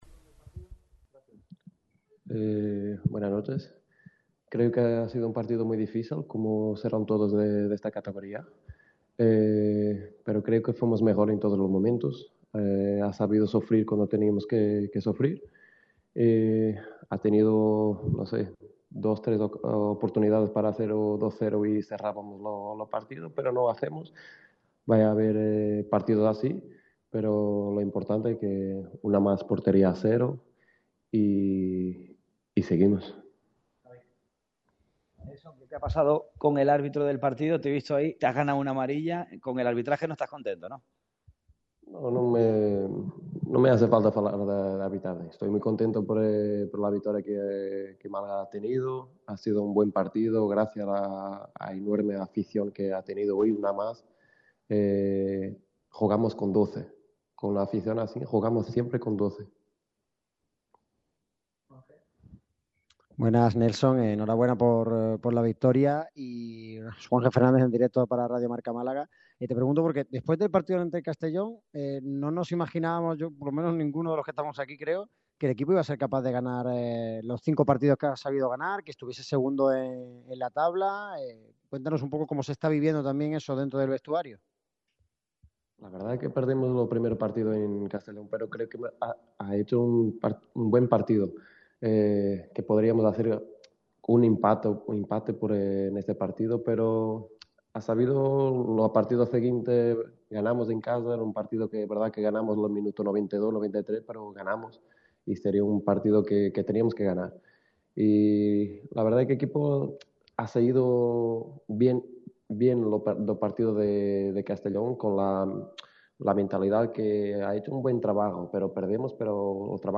Al término del partido en La Rosaleda habló Nélson Monte. El central malaguista valoró el trabajo y la victoria ante el San Fernando.